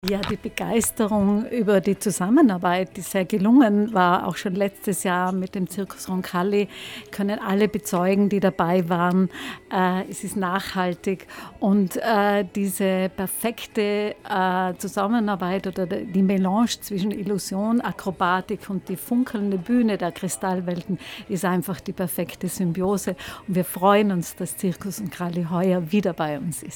AufnahmeorteSwarovski Kristallwelten